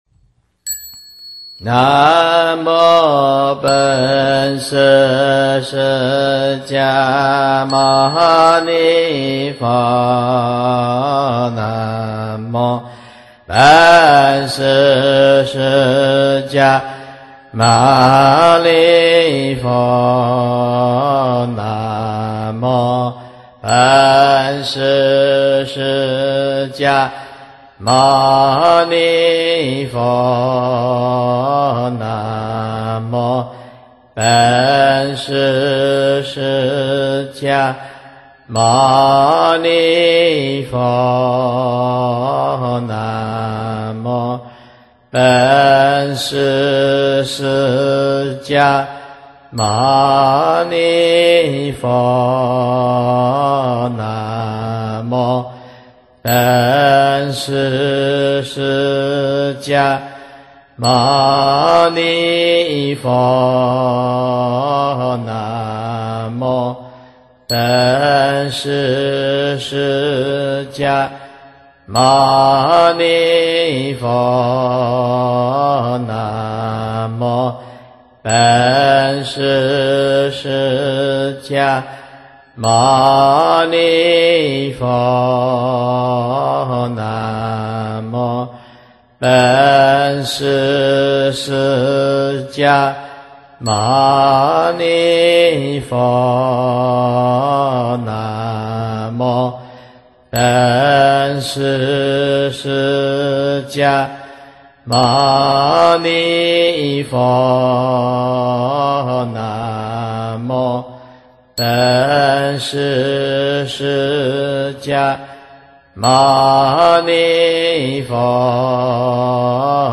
本师圣号念诵，节奏平稳，适合早晚课及日常持名。